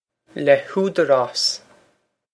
Pronunciation for how to say
leh hoo-der-aws